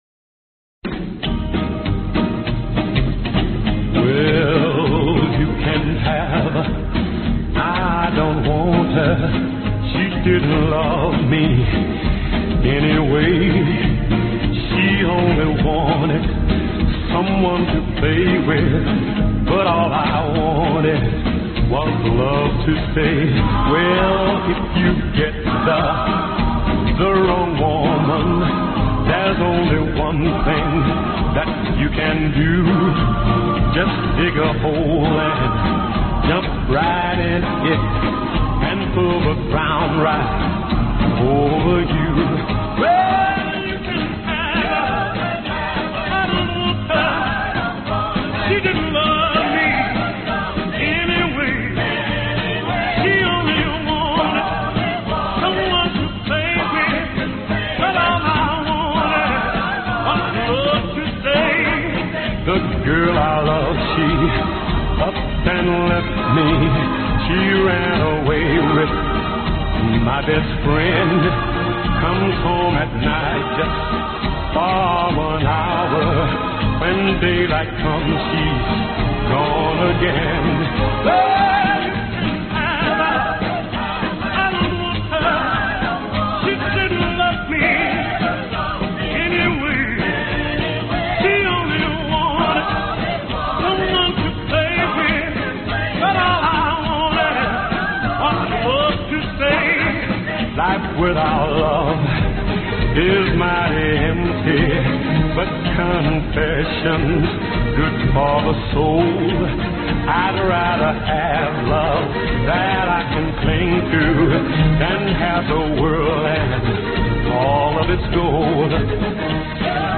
A Tribute To Old Time Country Music